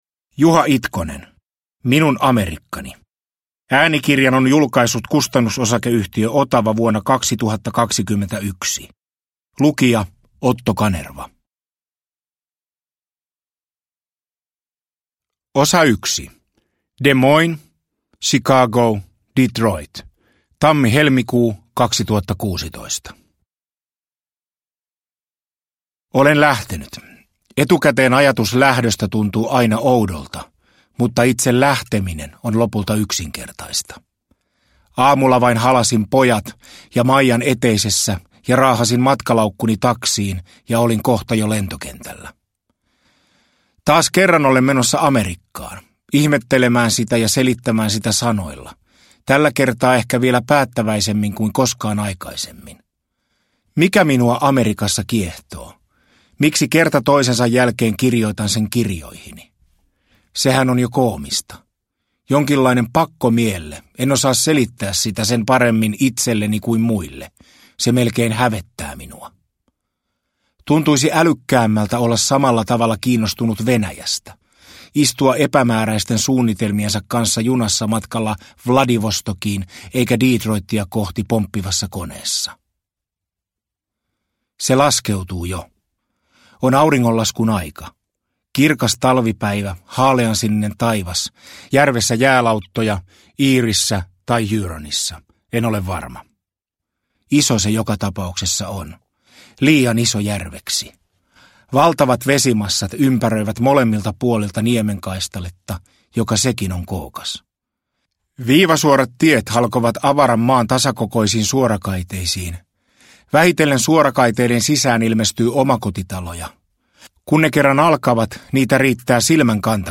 Minun Amerikkani – Ljudbok